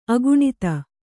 ♪ aguṇita